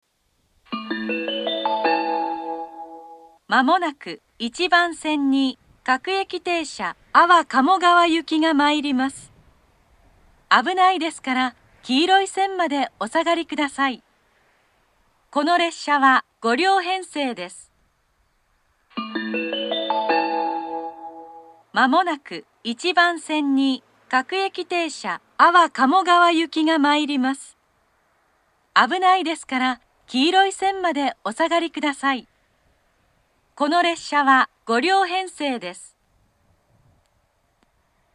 namegawa-island-1bannsenn-kudari-sekkinn.mp3